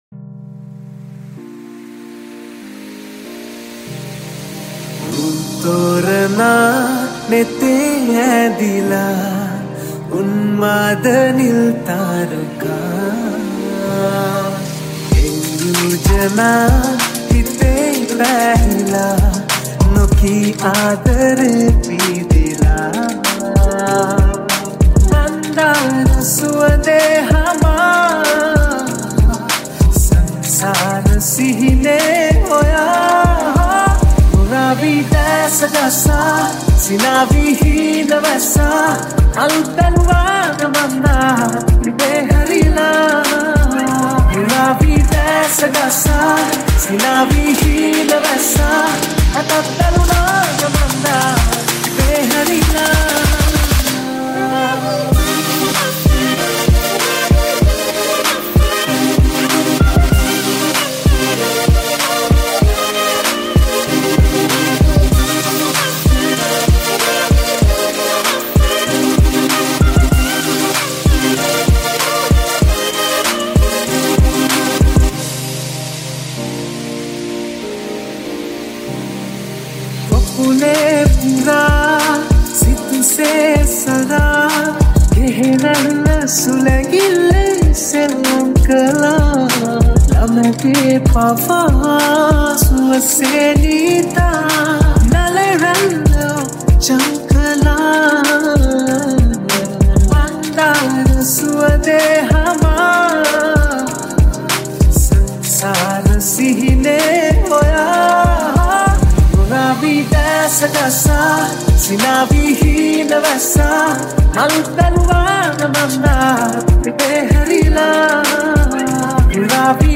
High quality Sri Lankan remix MP3 (3.2).